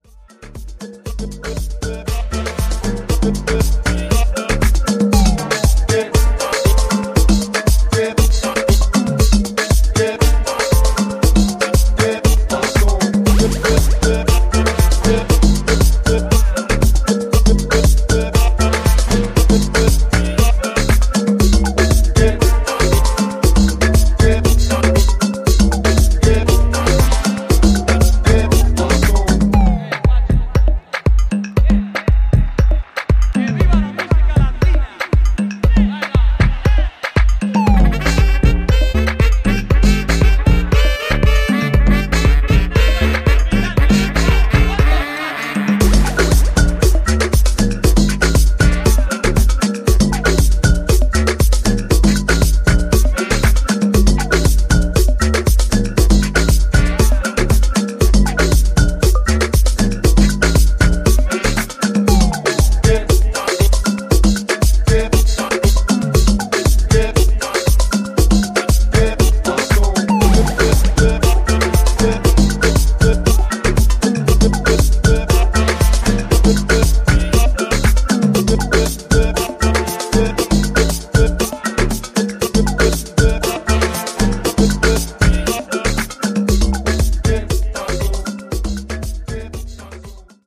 ジャンル(スタイル) DEEP HOUSE / AFRO